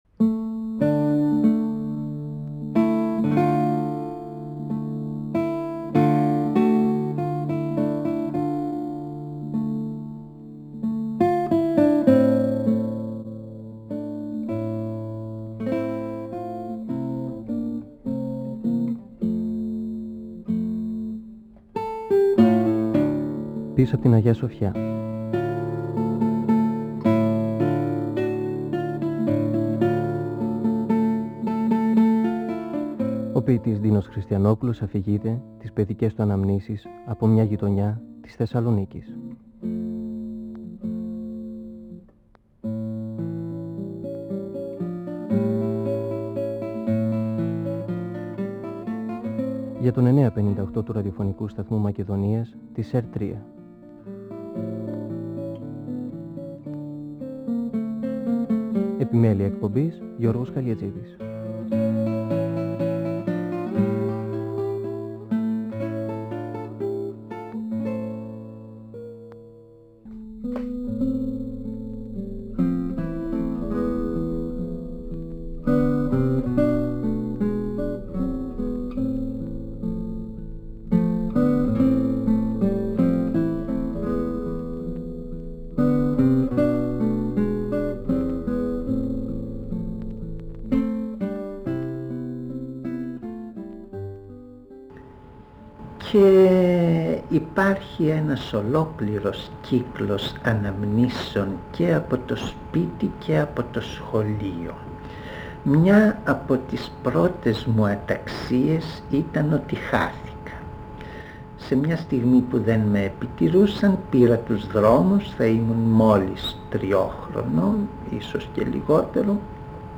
(Εκπομπή 3η) Ο ποιητής Ντίνος Χριστιανόπουλος (1931-2020) μιλά για τις αναμνήσεις του από το σχολείο.